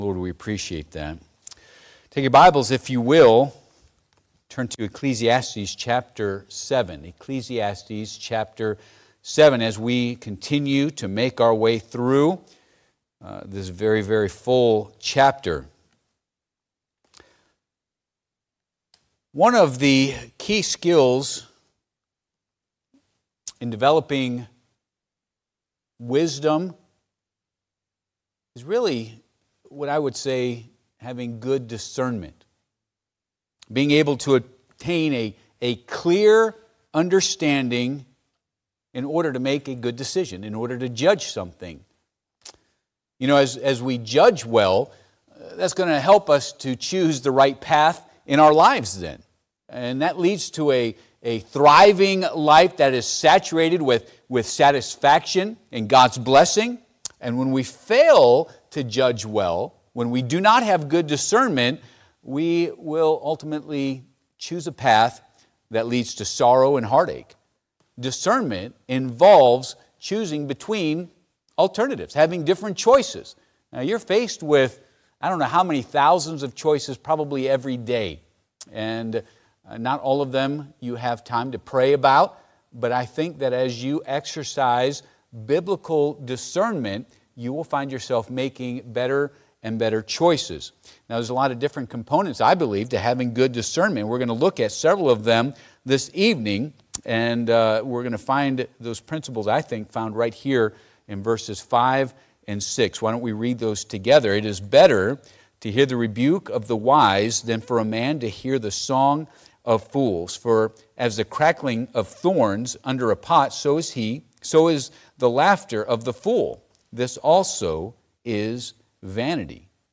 Service Type: Midweek Service